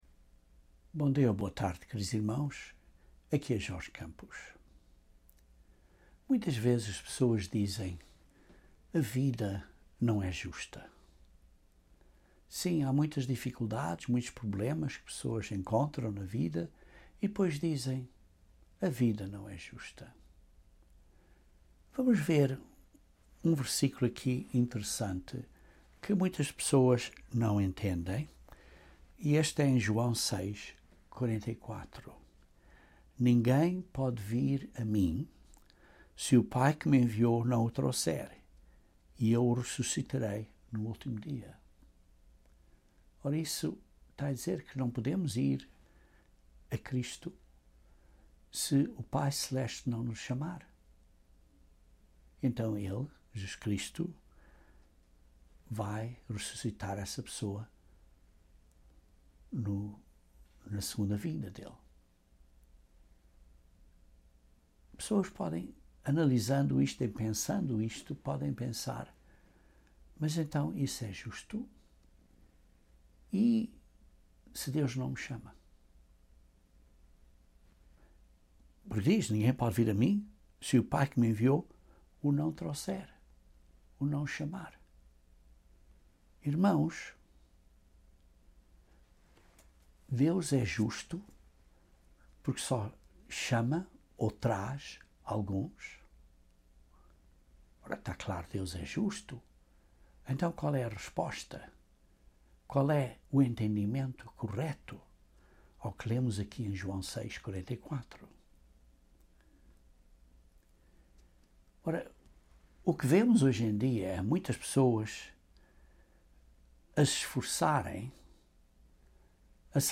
O milénio e a segunda ressurreição também serão um periodo de salvação e ainda muito maiores do que hoje. Este sermão explica este mistério de Deus.